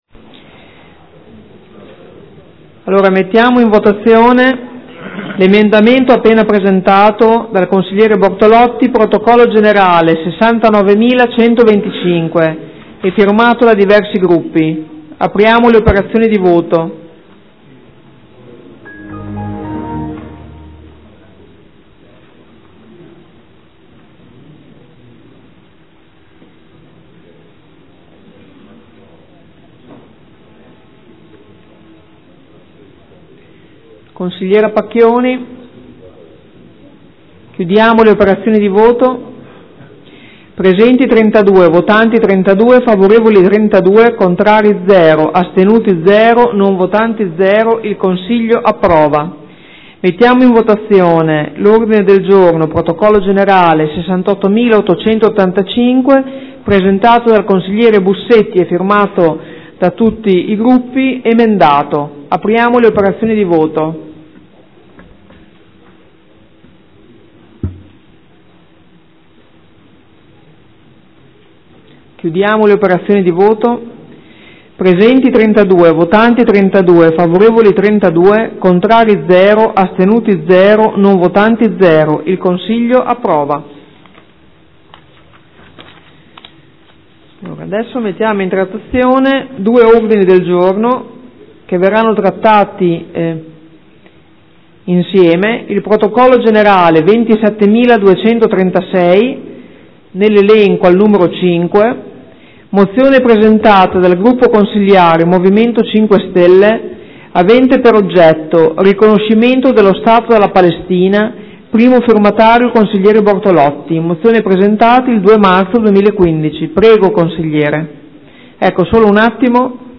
Seduta del 21/05/2015 mette ai voti emendamento 69125: approvato. Mette ai voti Og 68885 emendato: approvato.
Presidentessa